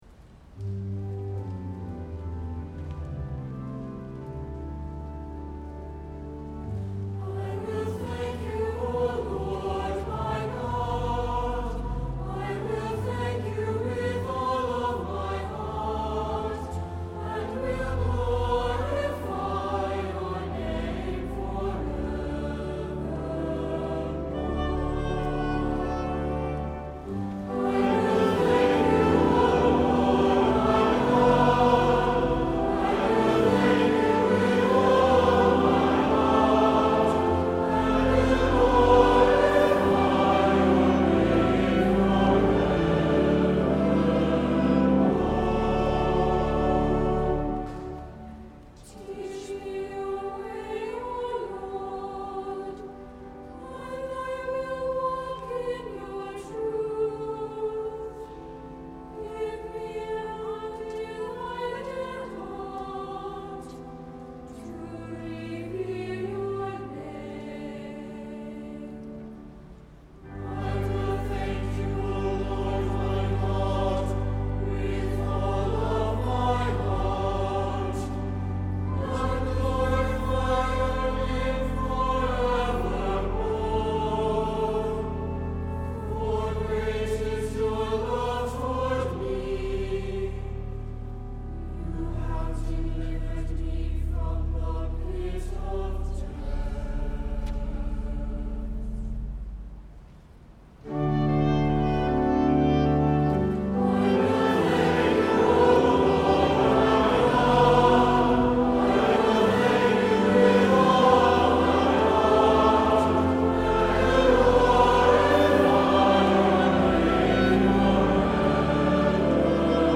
SATB, Oboe, Assembly, Organ